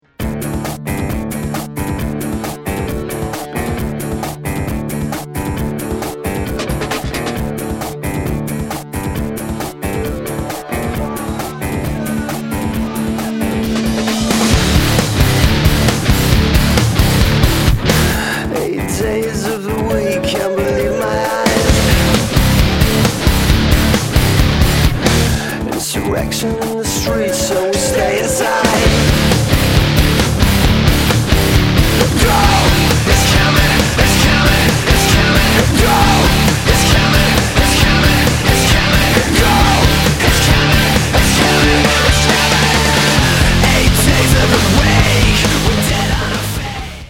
techno punkers